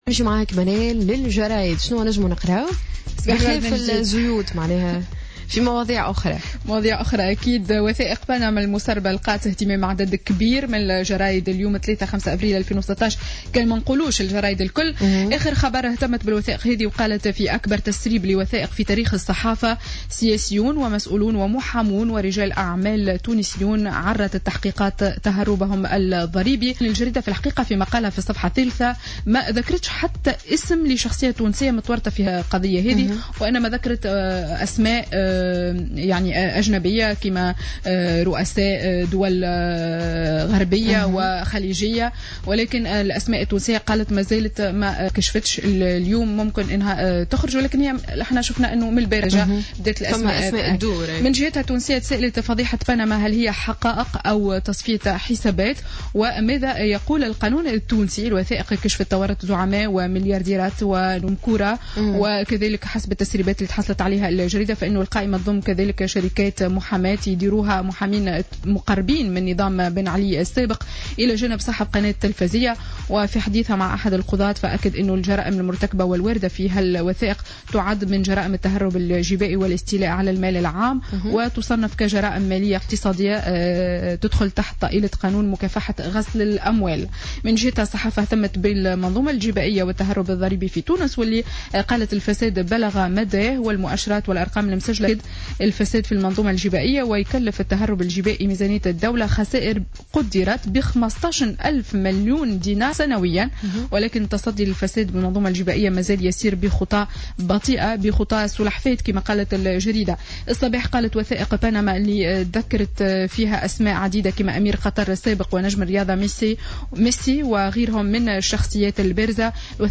Revue de presse du mardi 5 avril 2016